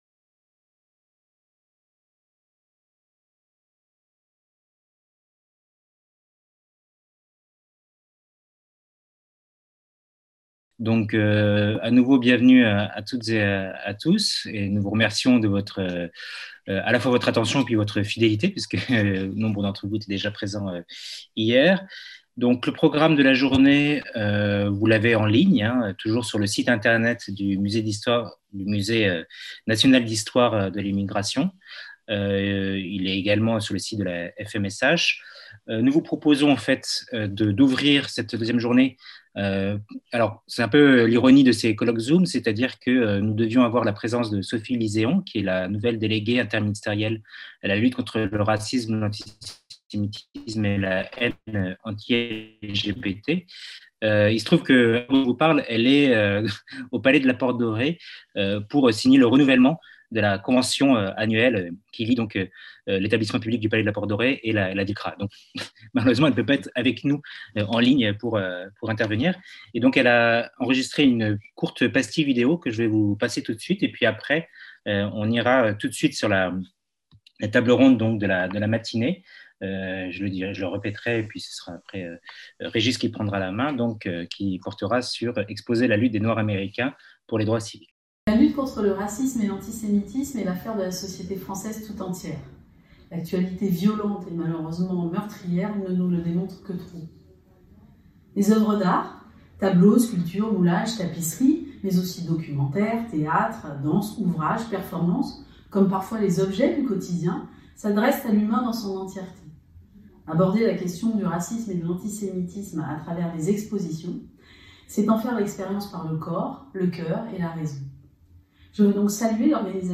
Colloque | 7 mai Le Musée national de l’histoire de l’immigration et la Plateforme internationale sur le Racisme et l'Antisémitisme PIRA (FMSH,EPHE) vous proposent un colloque international autour des traitements du racisme et de l'antisémitisme dans les expositions muséales.